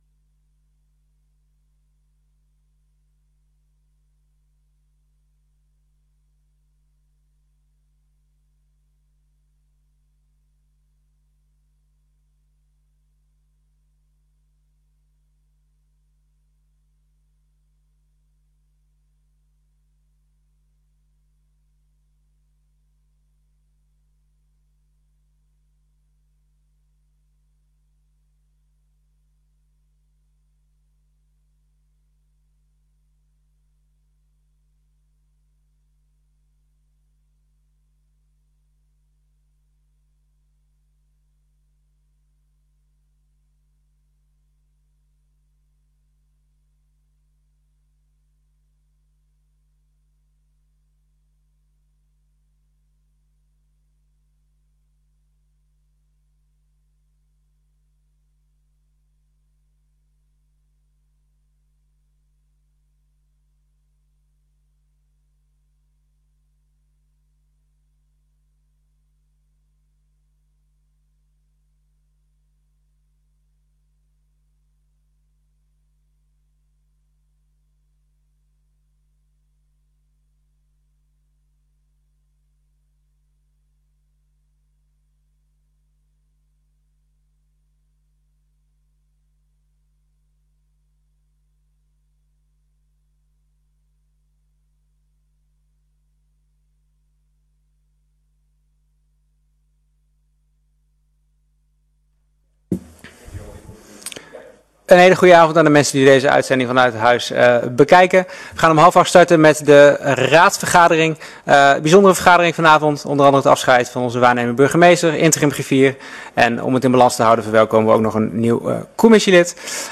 Gemeenteraad 07 april 2025 19:30:00, Gemeente Dalfsen
Download de volledige audio van deze vergadering